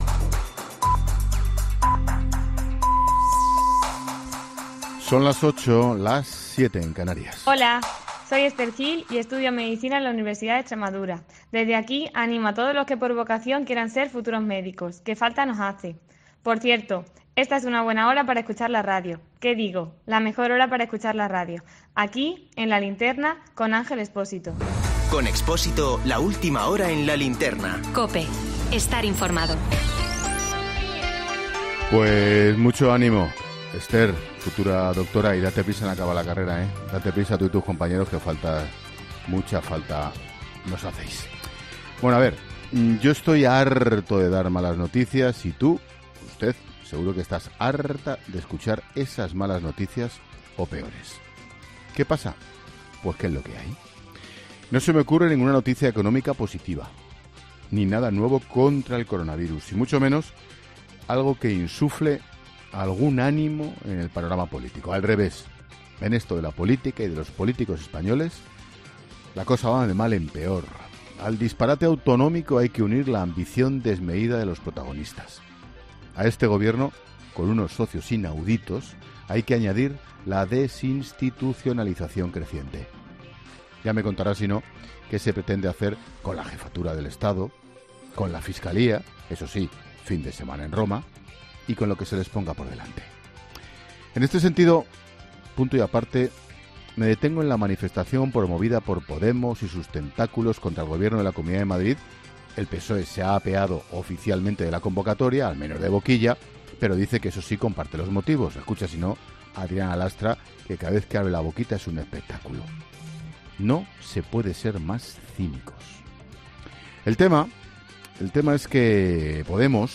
Monólogo de Expósito
El director de 'La Linterna', Ángel Expósito, analiza la gestión del Gobierno con esta pandemia